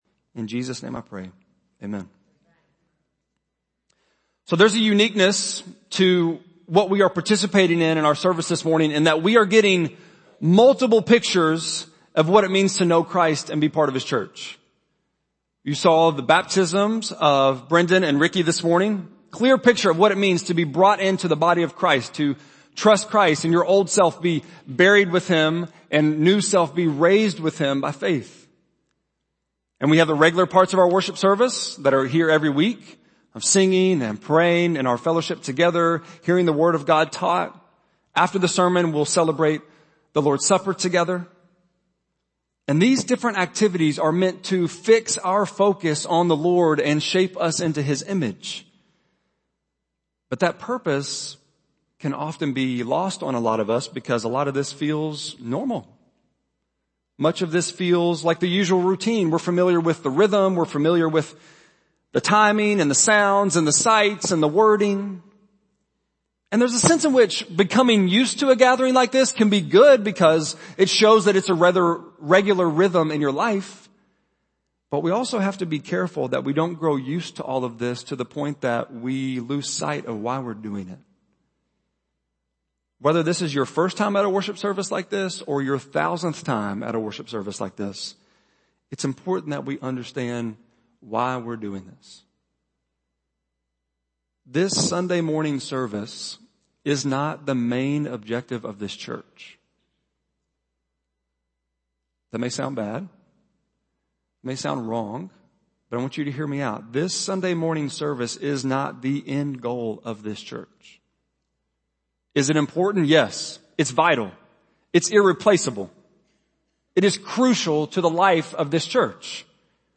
8.22-sermon.mp3